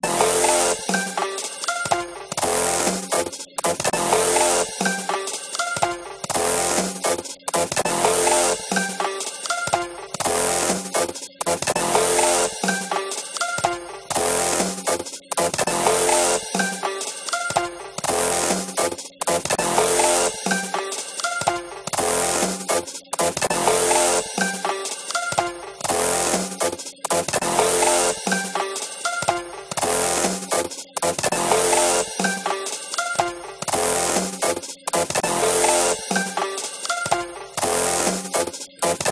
ゆったりとしたイメージのループ曲です。
明るい感じ。